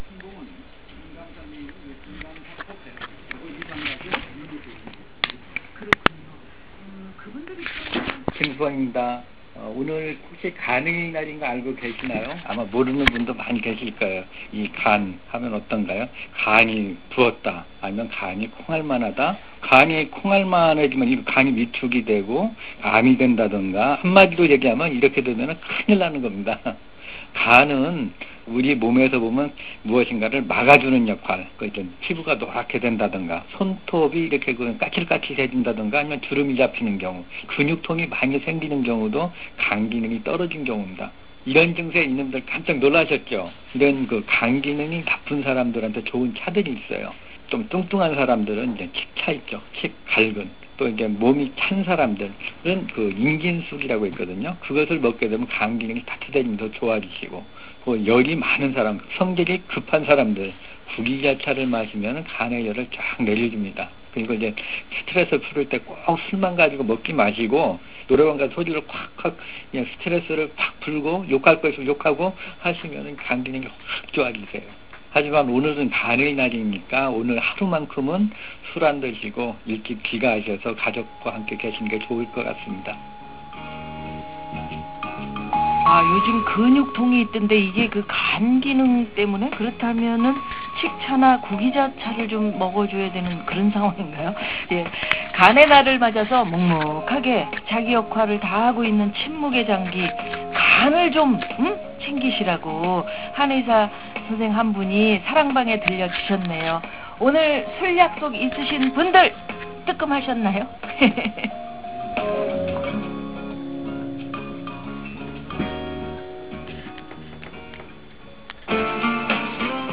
인터뷰내용...